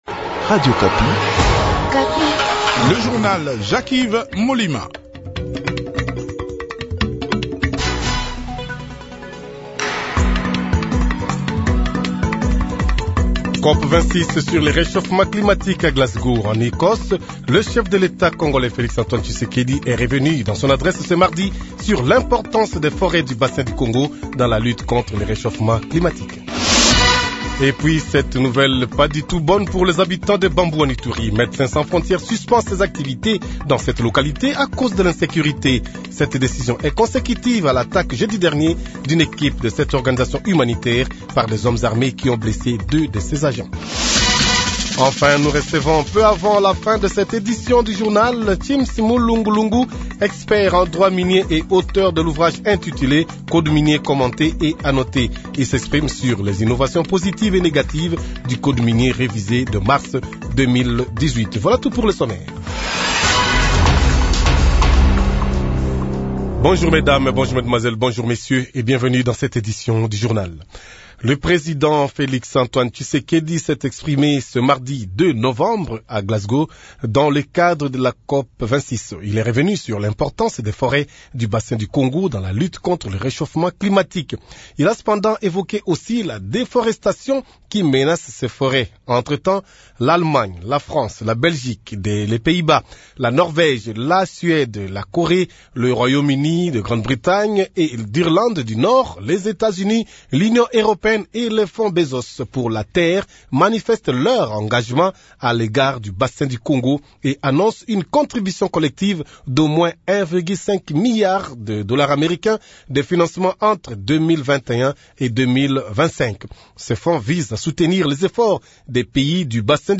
Journal Midi | Radio Okapi